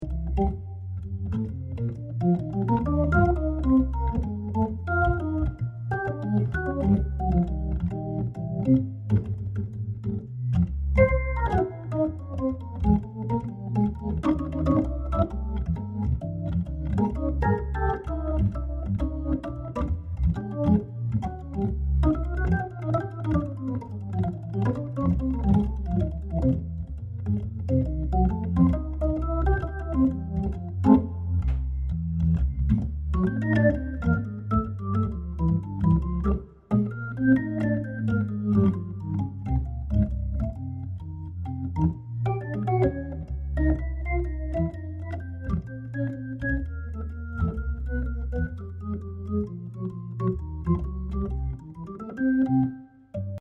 impro